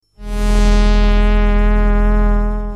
SONS ET SAMPLES DU SYNTHÉTISEUR OBERHEIM MATRIX 1000